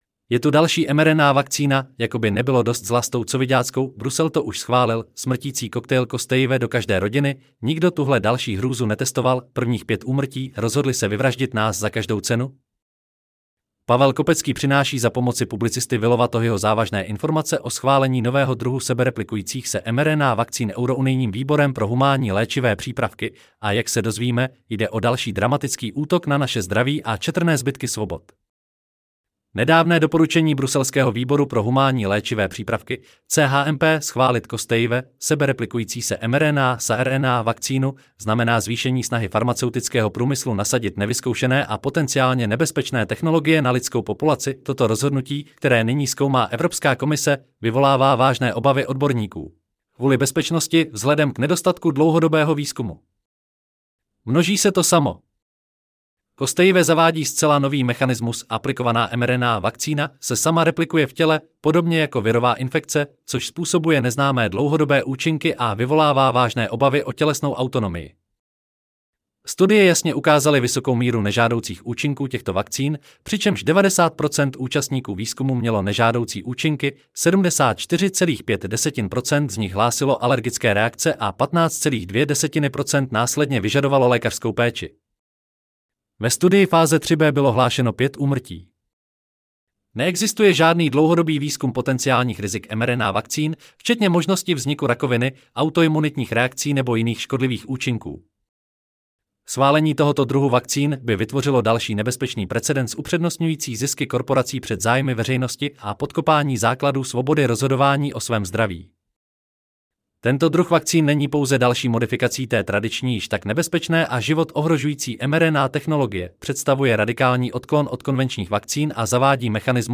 Celý článek si můžete poslechnout v audioverzi zde: Je-tu-dalsi-mRNA-vakcina_Jako-by-nebylo-dost-zla-s-tou-covidackou.Brusel-to-uz-schvalil.Smrtici 17.1.2025 Je tu další mRNA vakcína: Jako by nebylo dost zla s tou coviďáckou.